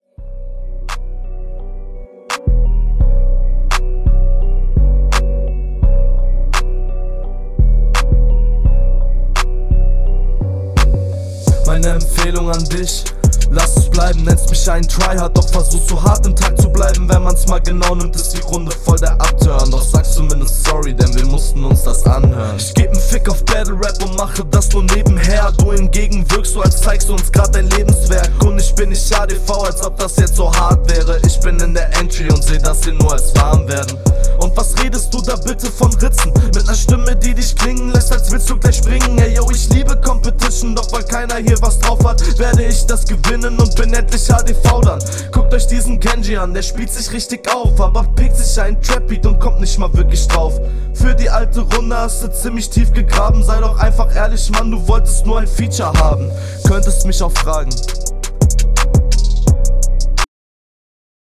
Die Spuren übersteuern am Anfang, entweder zu laut exportiert oder aufgenommen.